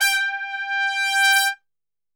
G 3 TRPSWL.wav